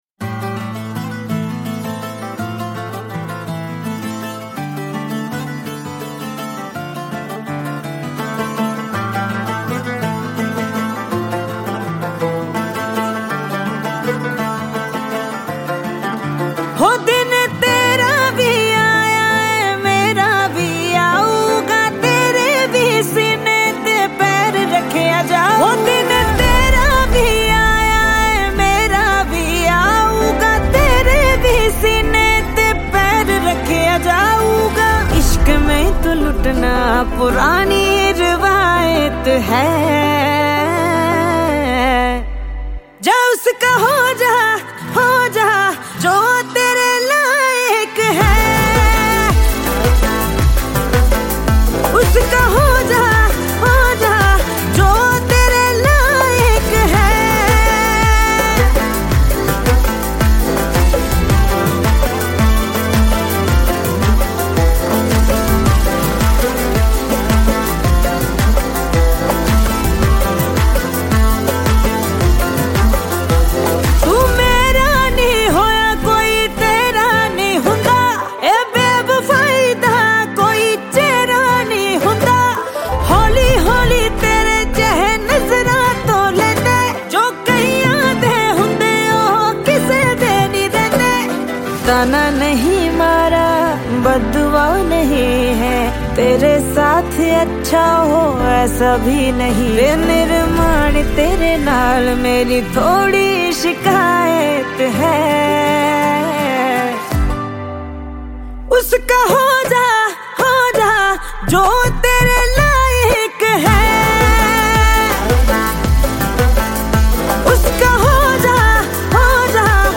Releted Files Of Haryanvi Gana 2025